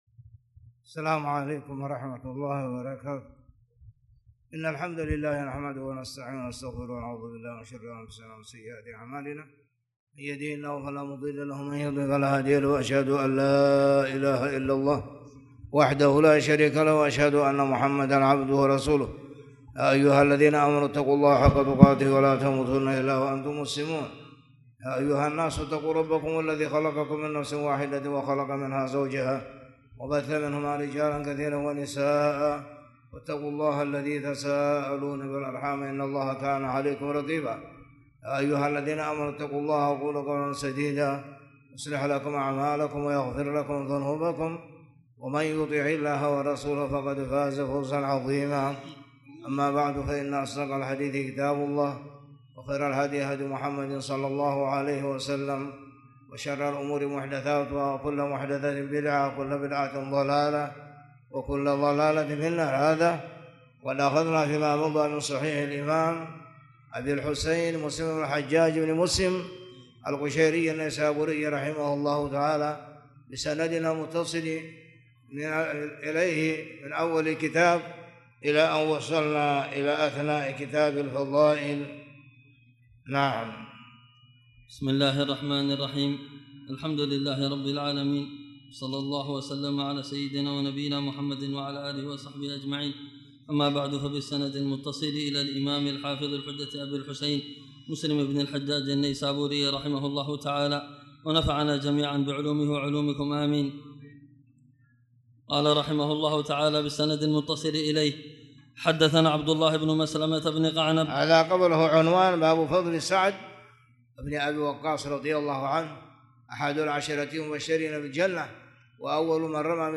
تاريخ النشر ٧ صفر ١٤٣٨ هـ المكان: المسجد الحرام الشيخ